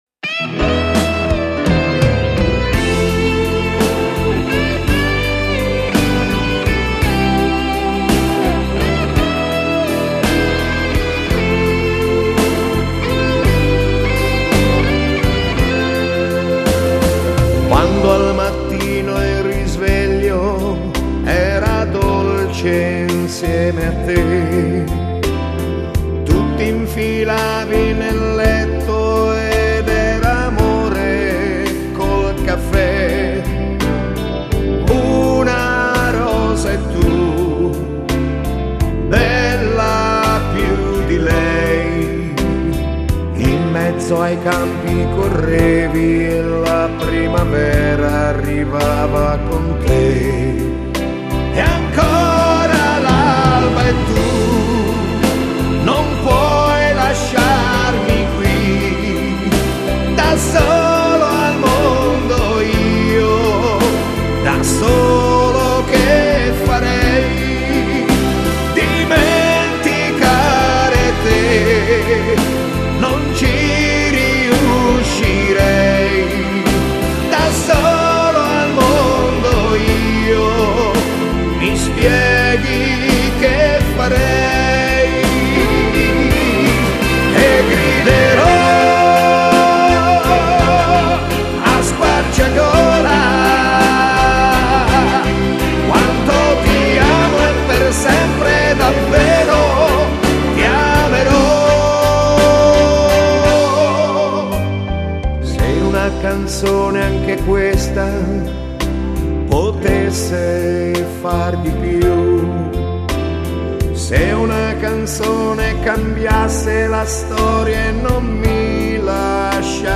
Genere: Terzinato